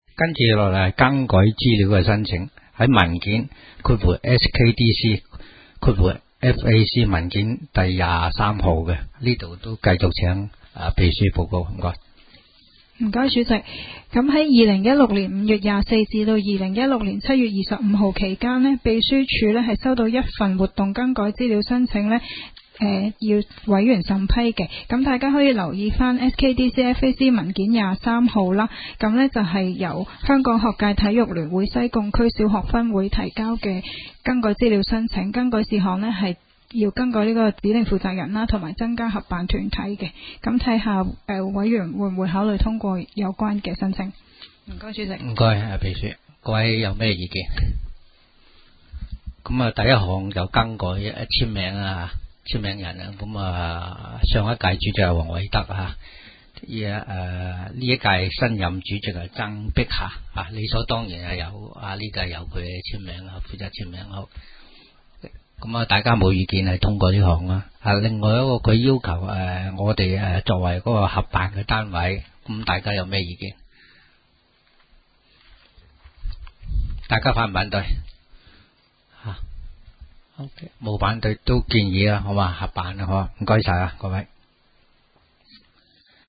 委员会会议的录音记录